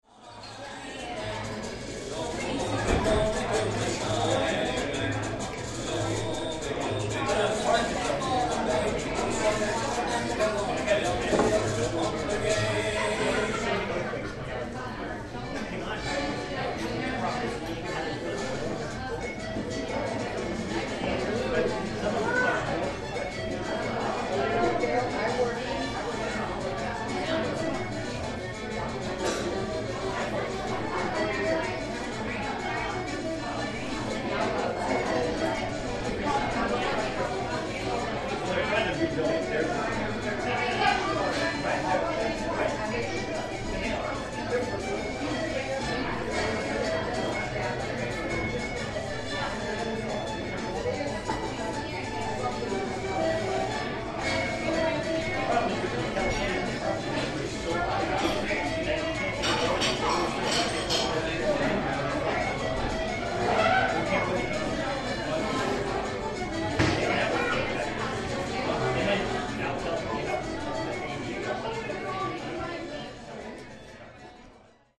今のところほぼ生音。
アメリカのパブ スポーツバー、 ステーキが美味しかった 01.15
pub.mp3